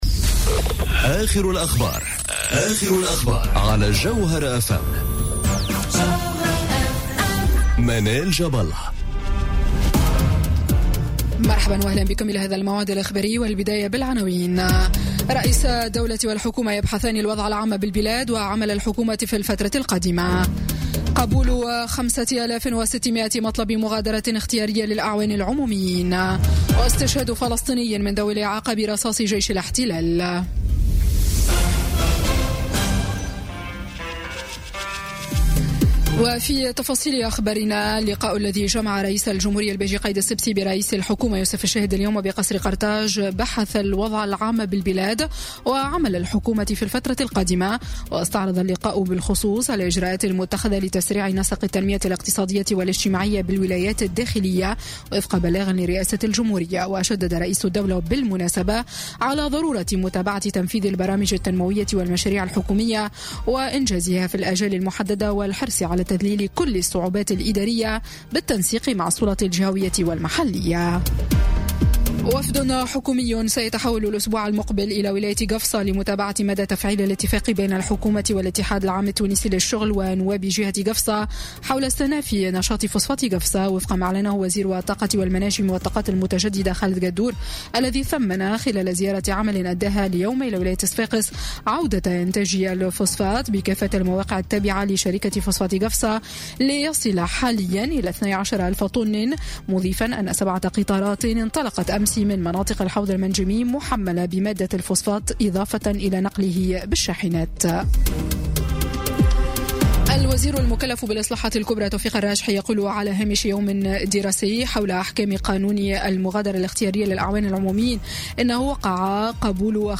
نشرة أخبار السابعة مساءً ليوم الجمعة 9 مارس 2018